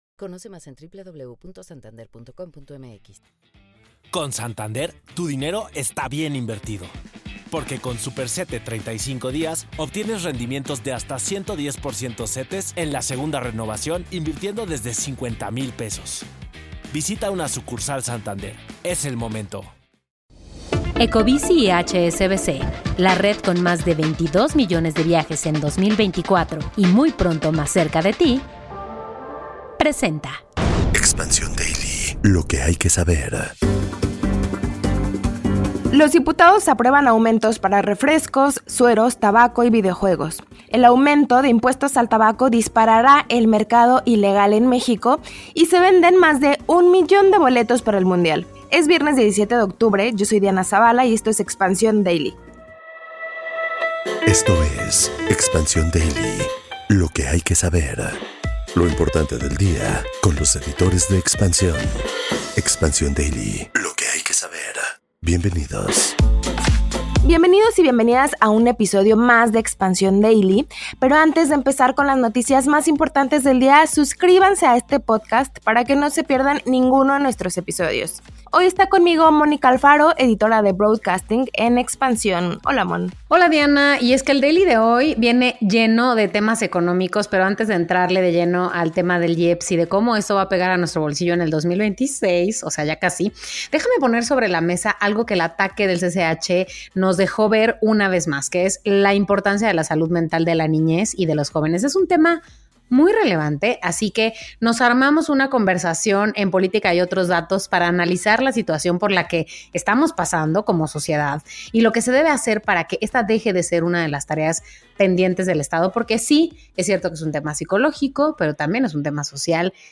un podcast de noticias de México